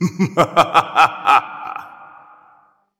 Evil Laugh
A menacing evil laugh building from a chuckle to a full villainous cackle
evil-laugh.mp3